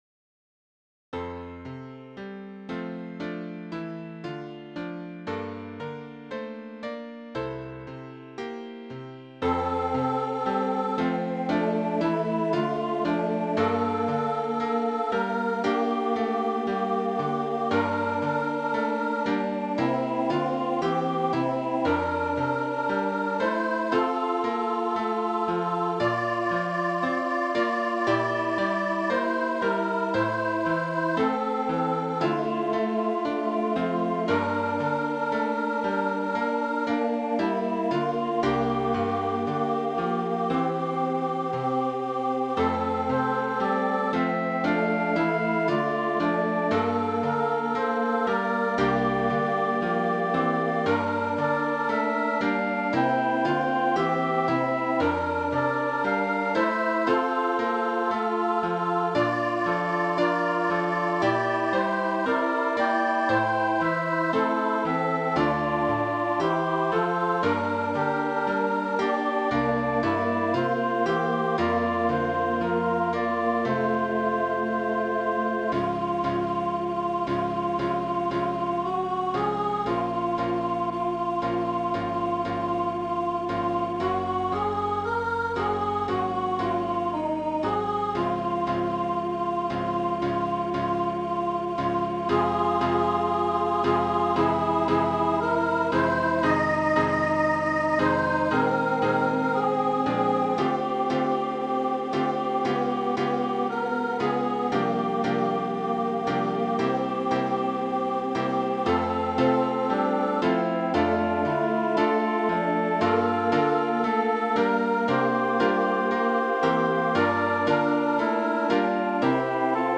Voicing/Instrumentation: Duet